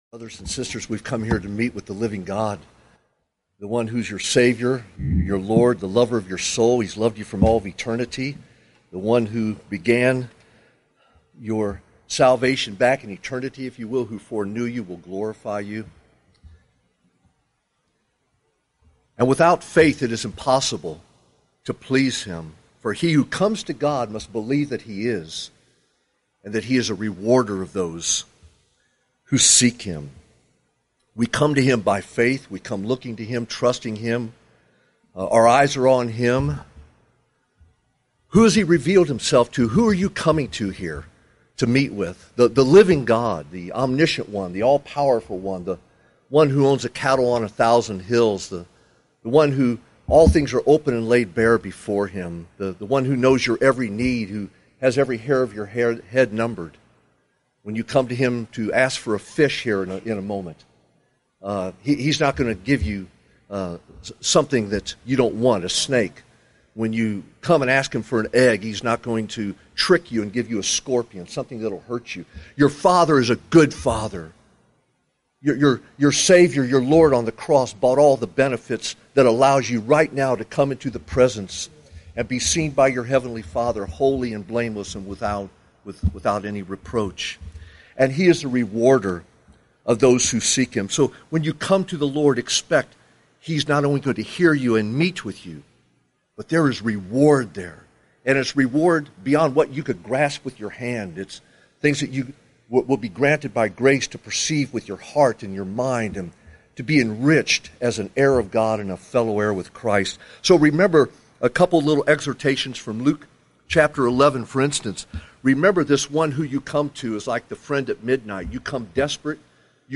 Prayer Meeting Exhortation | 3:30 | In our prayers there needs to be persistence and boldness. God will willingly give us what we ask Him for because of what Christ has purchased for us on the cross.